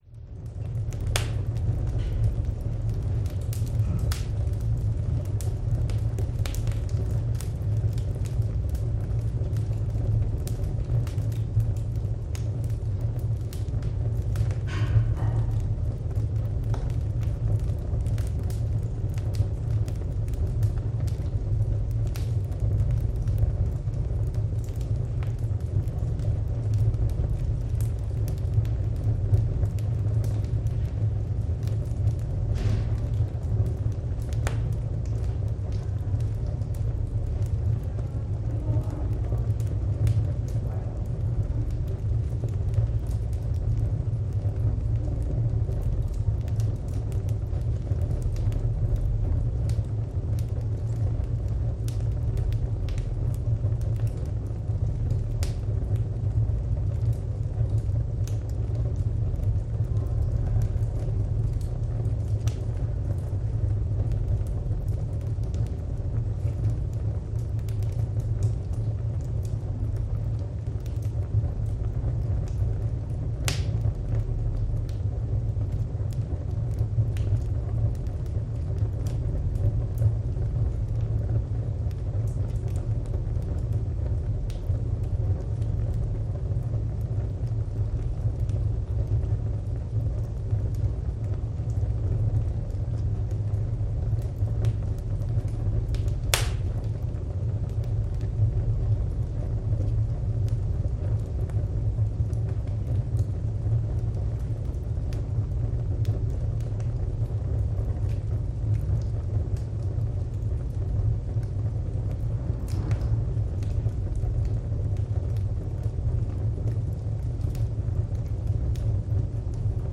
Interactive objects and environments (e.g. fire, water, forest) emit soothing sounds for deeper immersion.
Fireplace
Fire.mp3